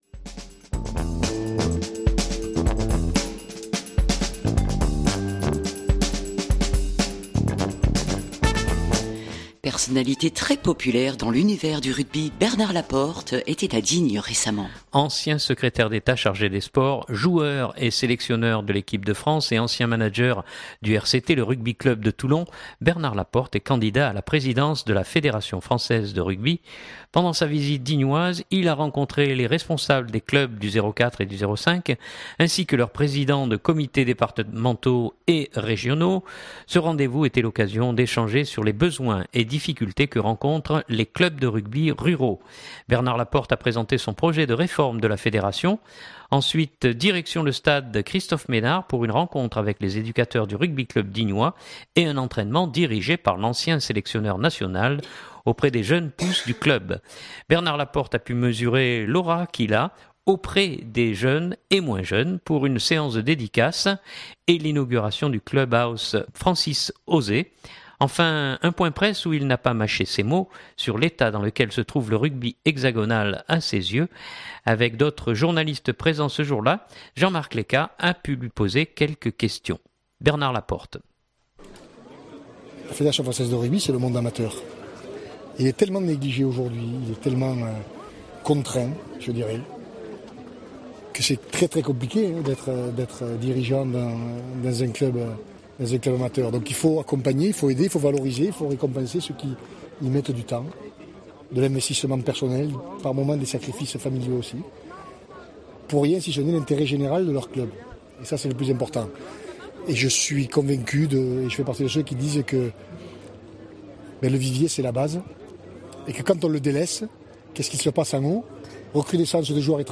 Enfin, un point presse où il n’a pas mâché ses mots sur l’état dans lequel se trouve le rugby hexagonal à ses yeux.